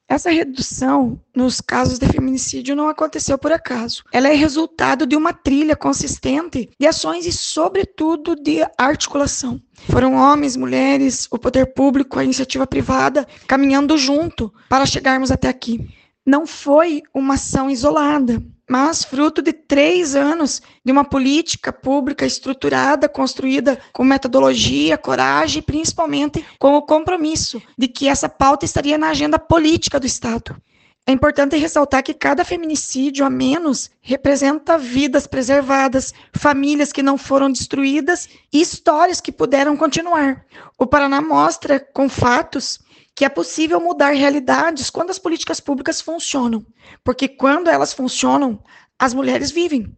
Sonora da secretária da Mulher, Igualdade Racial e Pessoa Idosa, Leandre Dal Ponte, sobre a redução no número de feminicídios no Paraná | Governo do Estado do Paraná